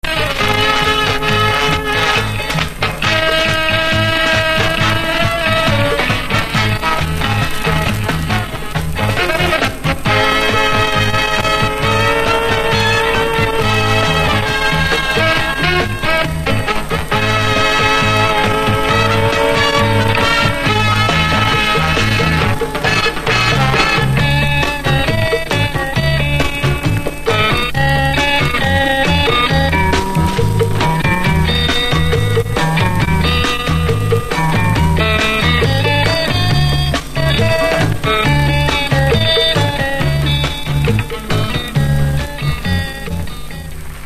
Фрагменты двух латиноамериканских танцевальных мелодий (запись 77-го года)
Предлагаю послушать короткие фрагменты танцевальных мелодий которые я записал с телевизора в 1977-м году (что это за танцы точно не знаю, но по видимому латиноамериканские).
latinoamerikanskaya-tantsevalnaya-melodiya-70-h-godov-1-(fragment).mp3